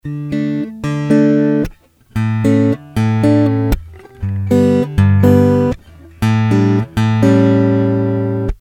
Я сыграл короткий отрывок (длиной от 10 до 15 секунд) тремя различными способами в мой рекордер Fostex Compact Flash:
Гитара, K4 и BenzBenz были настроены на "плоскую", без каких-либо эффектов.
Исходя из того, что я услышал через мой компьютер, K4 (2) был более полным, а стрит (1) в порядке.